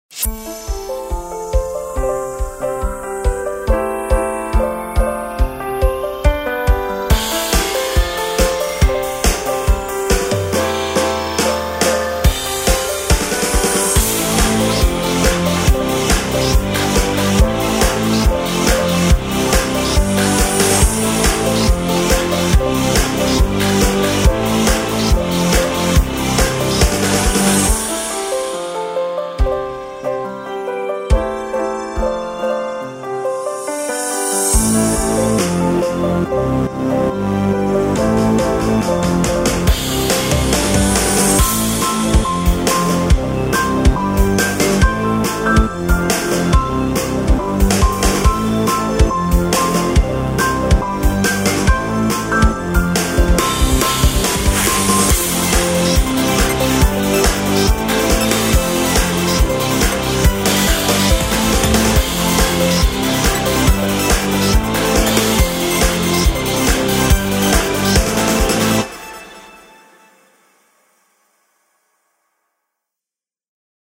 BGM スローテンポ ニューエイジ